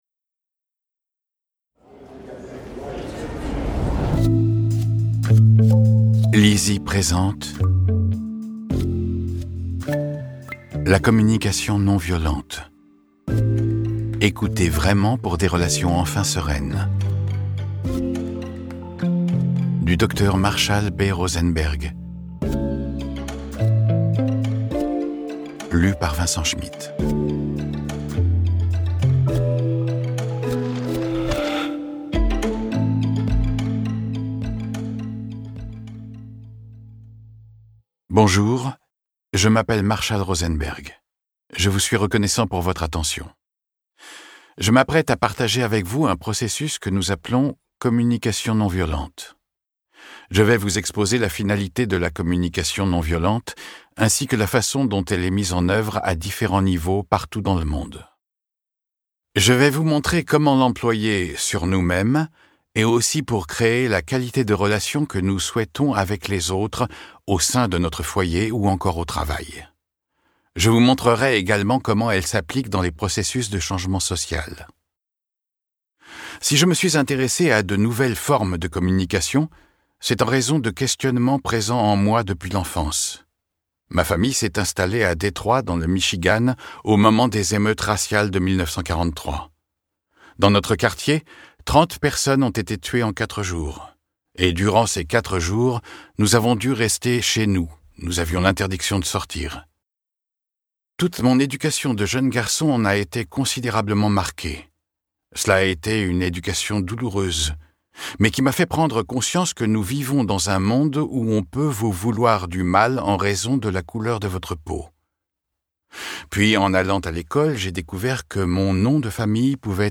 Cette conférence présente un modèle immédiatement applicable pour pacifier les échanges au quotidien.
Une conférence de Marshall Rosenberg, exclusivement disponible à l'audio, pour la première fois traduite en France.